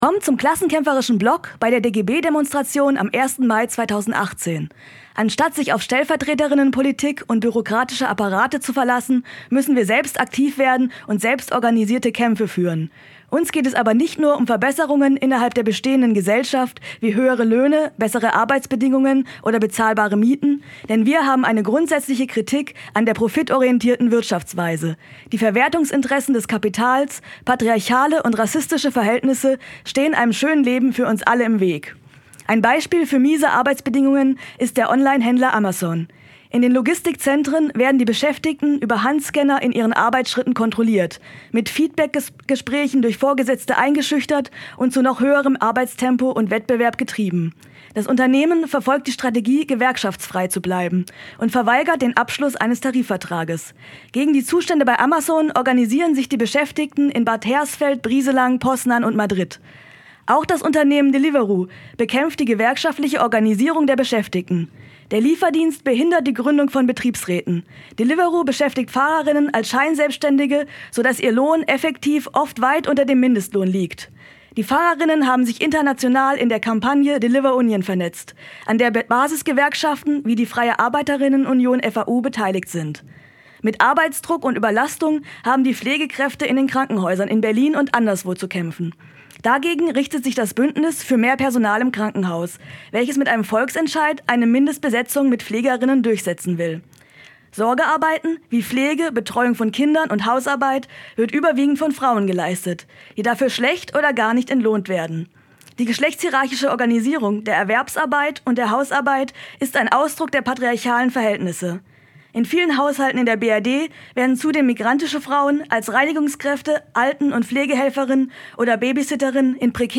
Wir veröffentlichen hier Reden verschiedener Strömungen als herunterladbare mp3-Dateien.